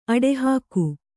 ♪ aḍehāku